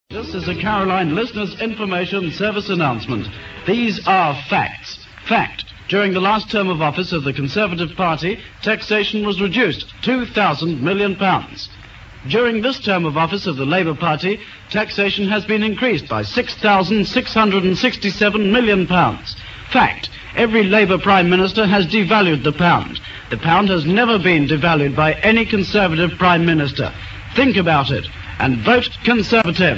station promotion on behalf of the Conservative Party